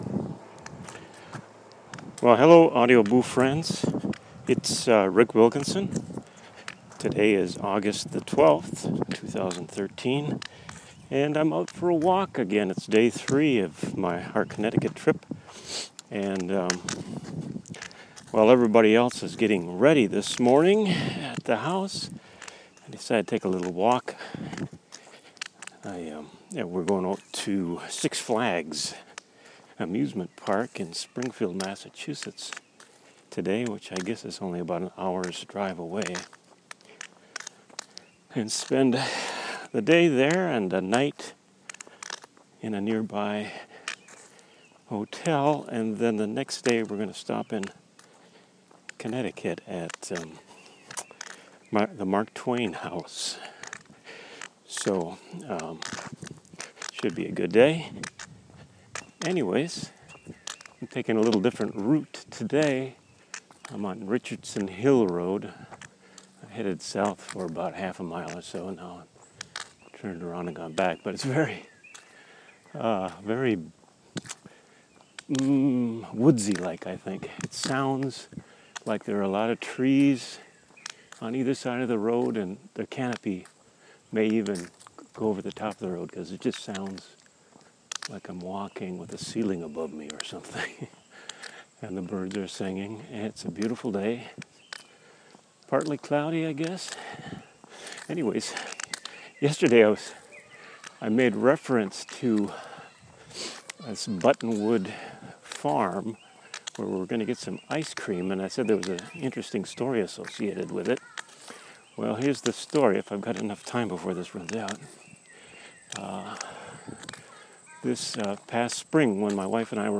A walk down a wooded road and a "Isn't it a small world" story.